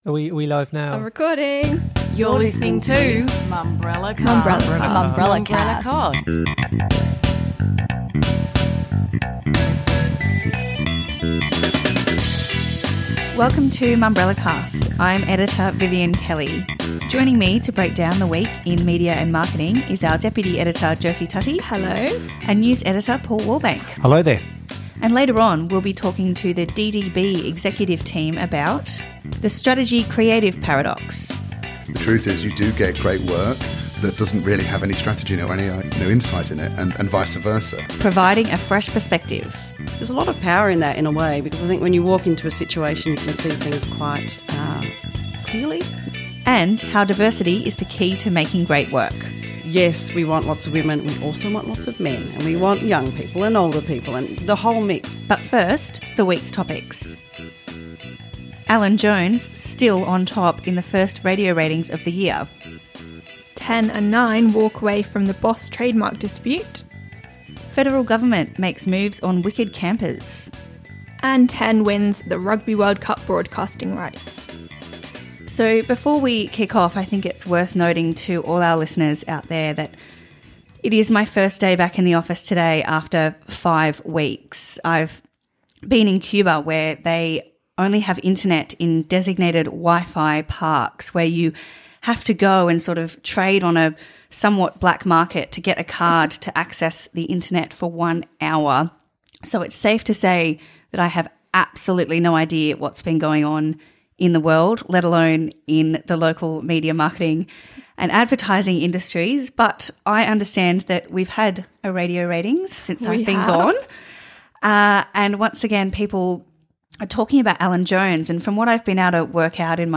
Plus, we’ve got a chat with the team from the newly-installed team at DDB Sydney (21:12). Throughout the wide-ranging talk we tackle the divide between strategy and creativity, how the new team has been able to provide a fresh perspective, and why diversity is the key to making great work.